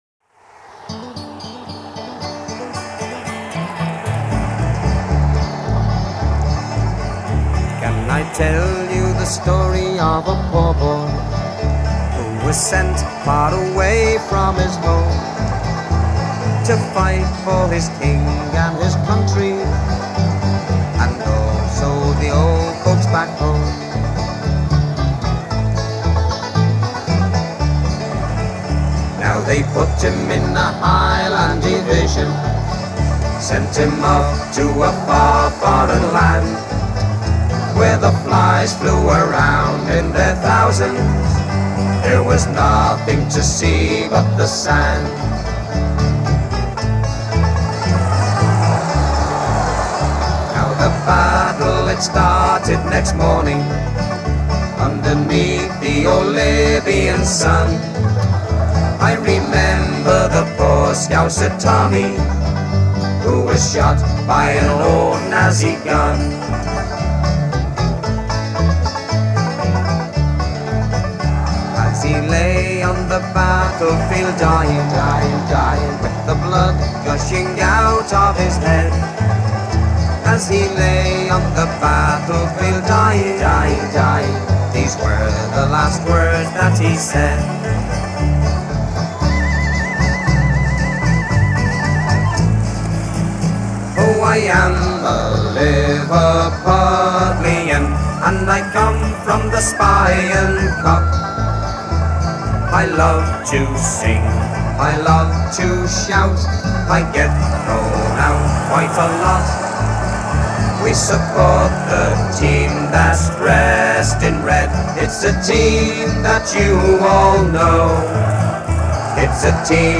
Песни болельщиков: